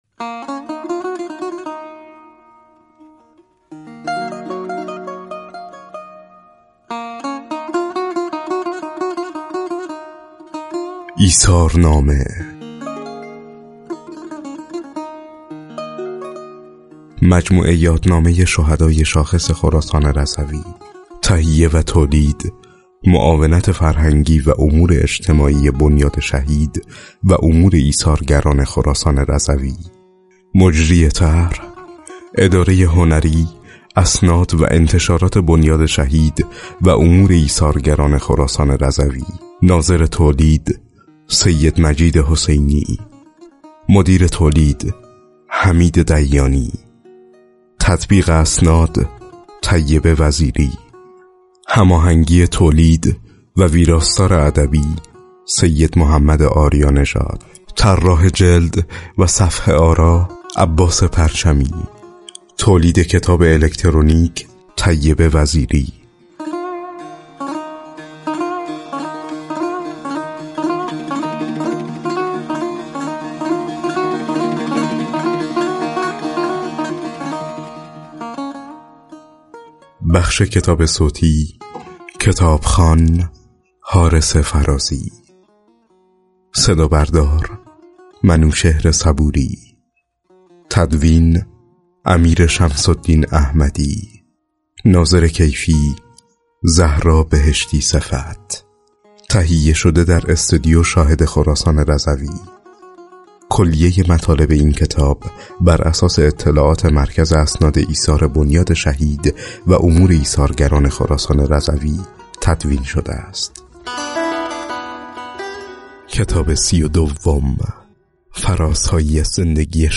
بر این اساس کتاب‌های جیبی، الکترونیکی، و کتاب صوتی 72 تن از شهیدان شاخص استان از میان شهیدان انقلاب اسلامی، ترور، دفاع مقدس، مرزبانی، دیپلمات و مدافع حرم منتشر و رونمایی شده است.